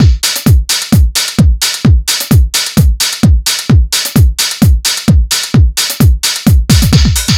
NRG 4 On The Floor 005.wav